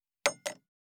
257,食器にスプーンを置く,ガラスがこすれあう擦れ合う音,カトラリーの音,食器の音,会食の音,食事の音,カチャン,コトン,効果音,環境音,
コップ効果音厨房/台所/レストラン/kitchen物を置く食器